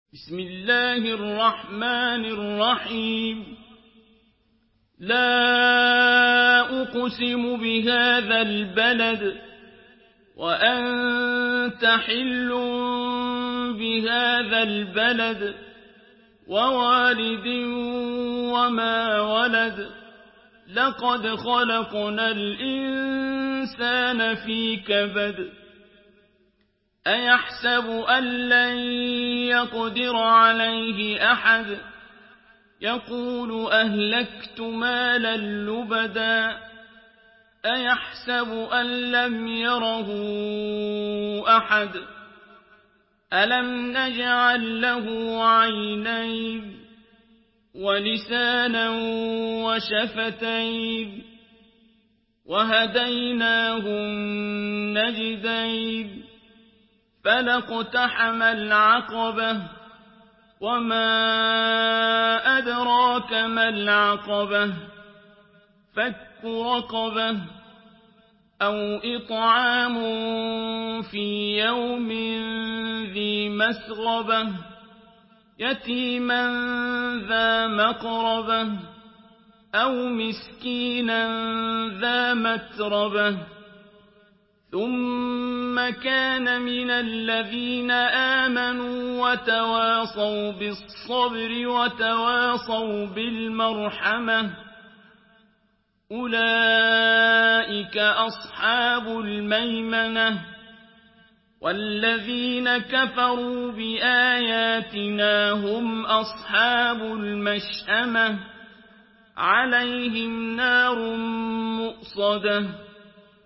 Surah Al-Balad MP3 in the Voice of Abdul Basit Abd Alsamad in Hafs Narration
Murattal Hafs An Asim